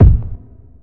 Never Die Kick.wav